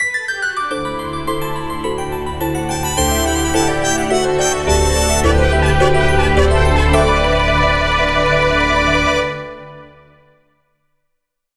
The tune that plays when a winner is about to be announced